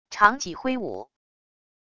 长戟挥舞wav音频